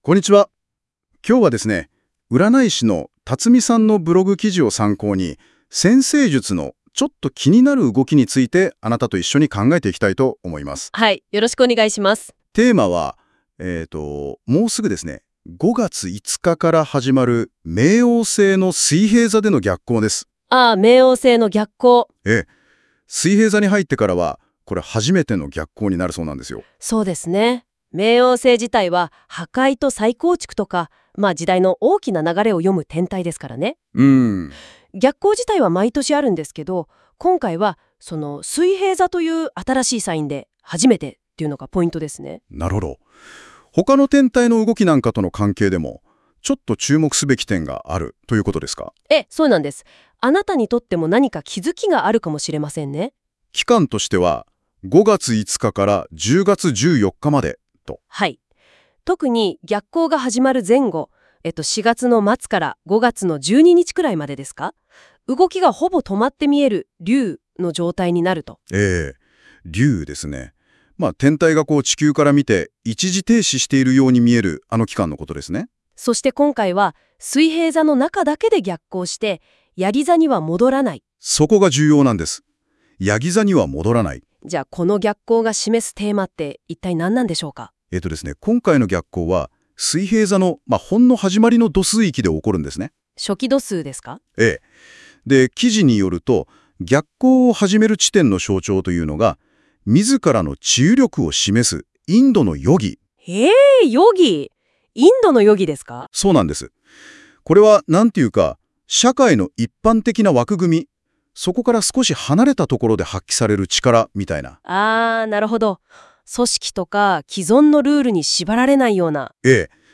このブログ「辰已の辻占」の記事「蠍座　満月　2025年」をもとに、notebookLMで生成したコンテンツです。